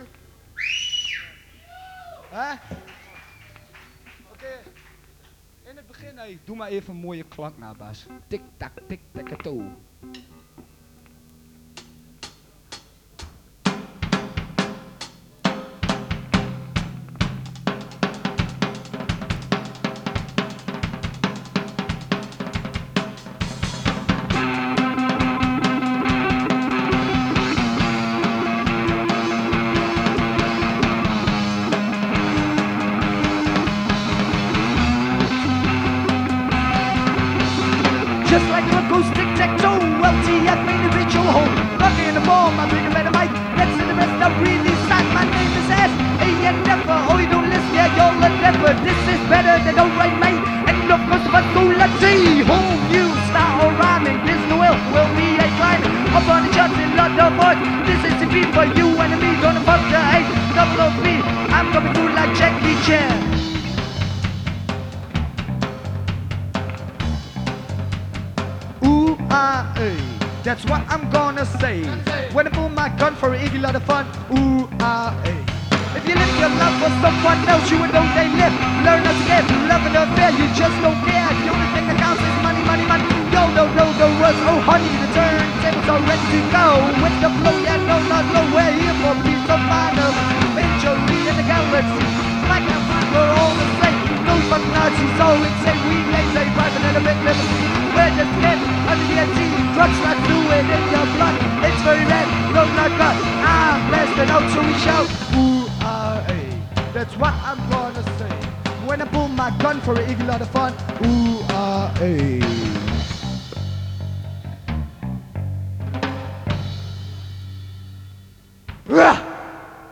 clock.wav
Onderdeel van Social behaviour - Live in Atak Enschede